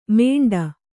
♪ mēṇḍa